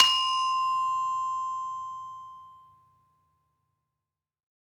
Gamelan Sound Bank
Saron-2-C5-f.wav